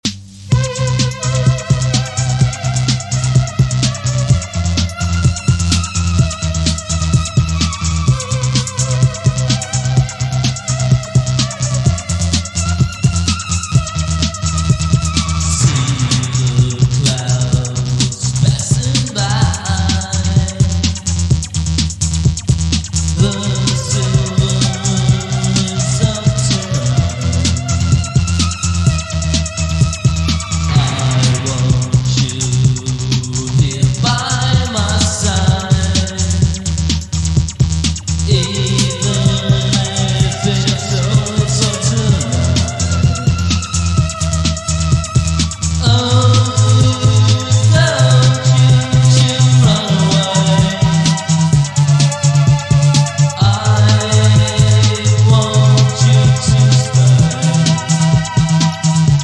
recorded in two short live takes directly onto one cassette
basic set up of Micromoog, Roland JX-3P and TR-606 Drumatix